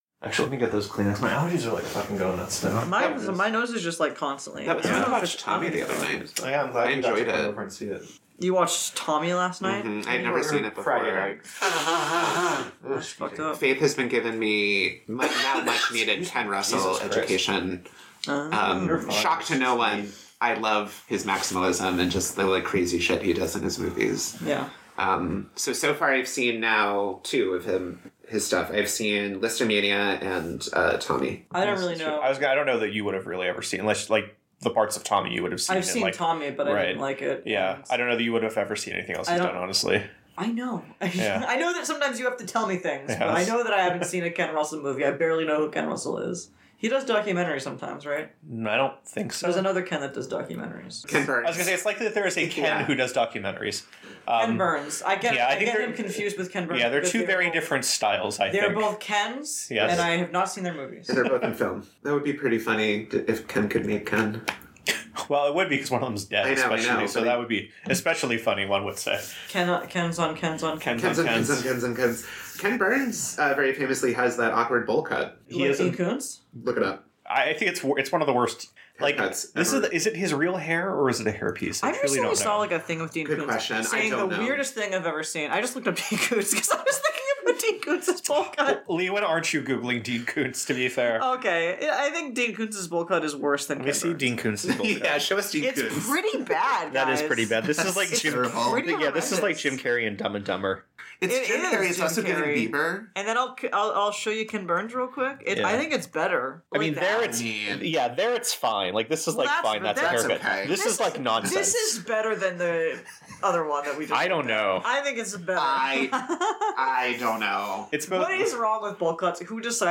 Three friends praising, destroying, and fixing the messiest, crappiest movies known to humanity. Oh, and we drink a lot, too.